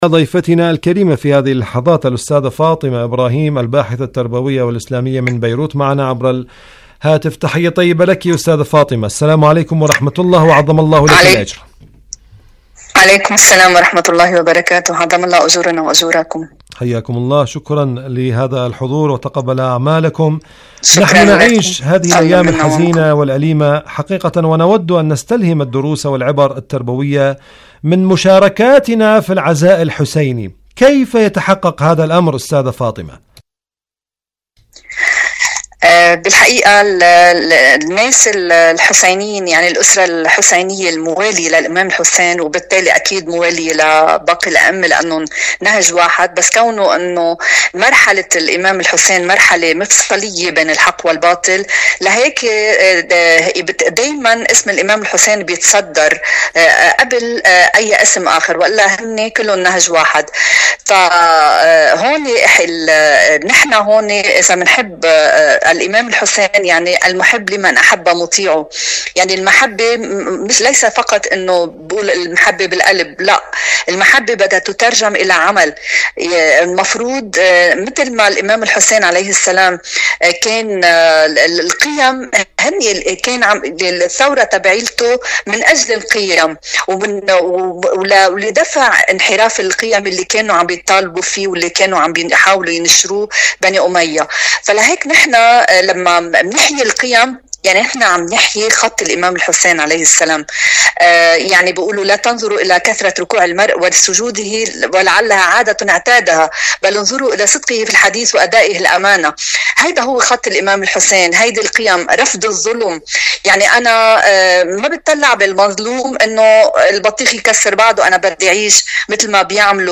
إذاعة طهران- معكم على الهواء: مقابلة إذاعية